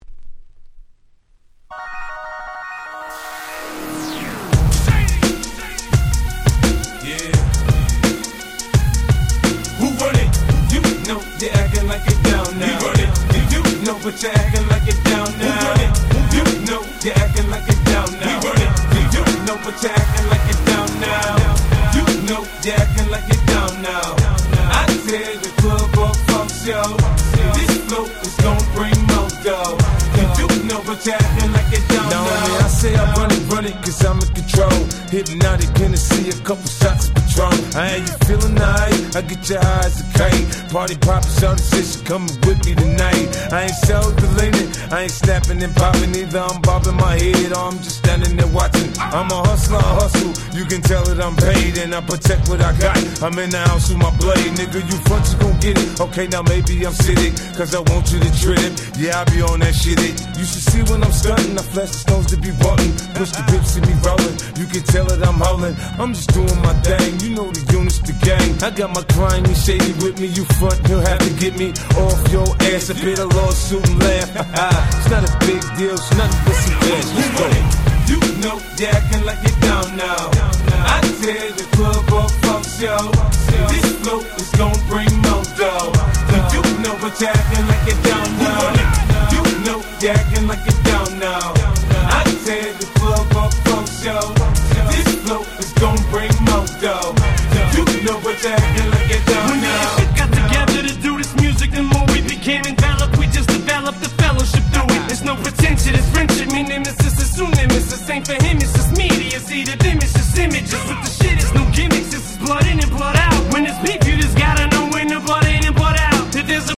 06' Big Hit Hip Hop !!
内容も想像通りのハーコーシット！！